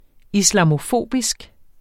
Udtale [ islɑmoˈfoˀbisg ]